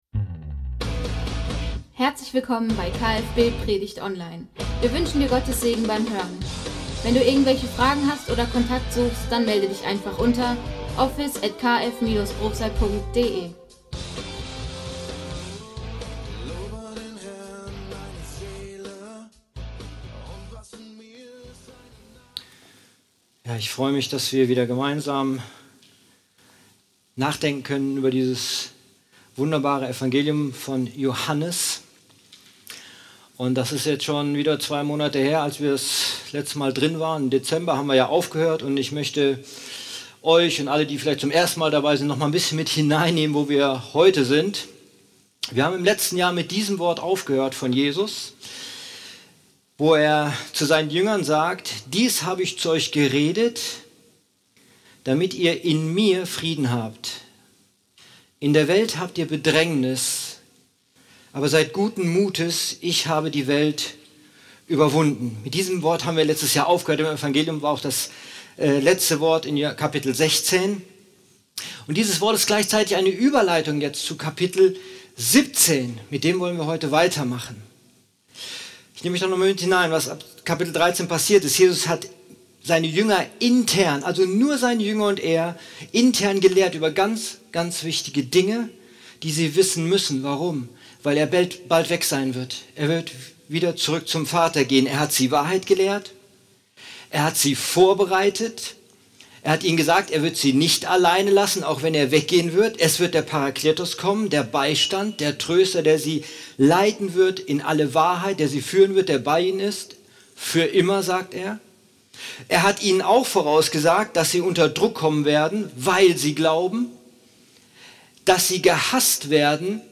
Gottesdienst: …und dann betet der Messias – Kirche für Bruchsal